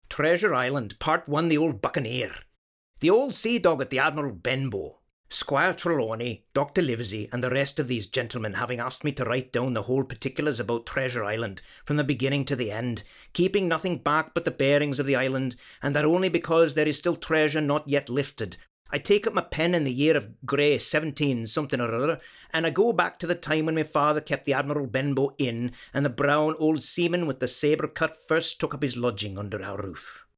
Jokaisella näytteellä luen englanninkielisen klassikkokirjan ensimmäisen kappaleen sen maan aksentilla, josta kirja on lähtöisin.
Skotlantilaisen aksentin kuulet Robert Louis Stevensonin Treasure Island -romaanin lukunäytteestä.